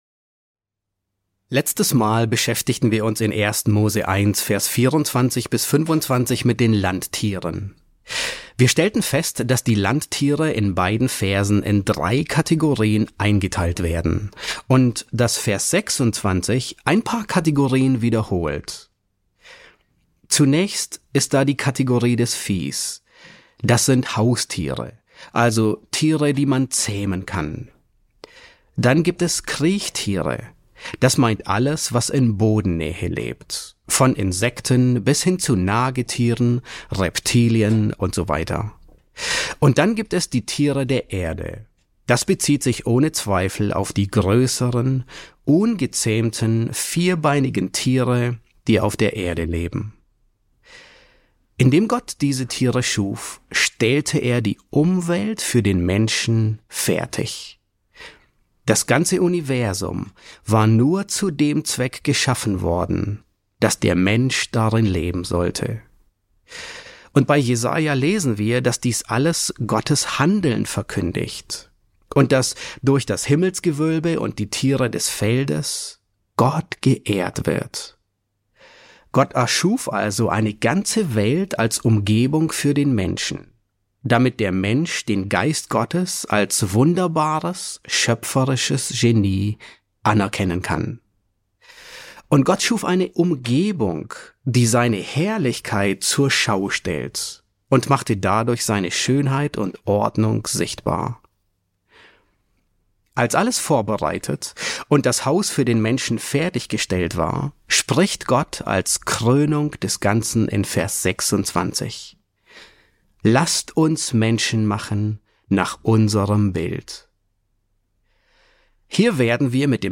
E12 S6 | Der 6. Schöpfungstag, Teil 3 ~ John MacArthur Predigten auf Deutsch Podcast